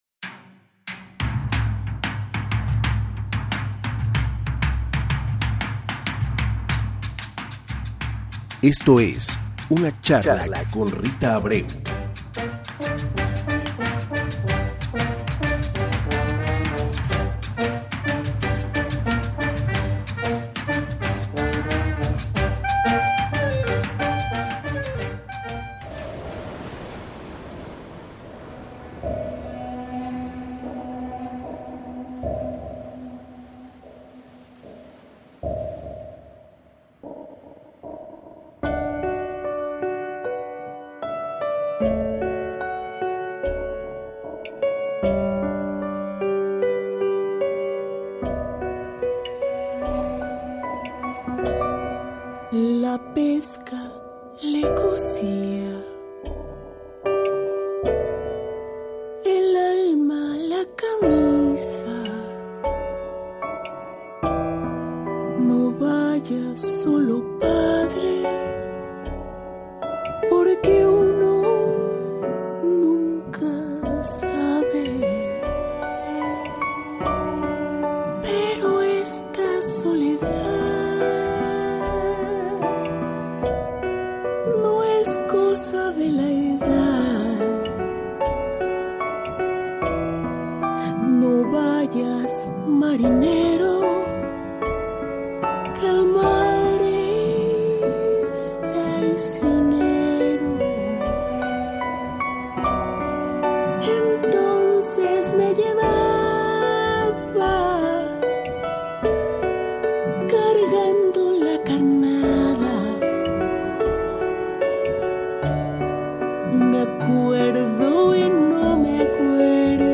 Hace unos días tuvimos el enorme placer de recibir en la cabina de Radio México Internacional a la cantante y actriz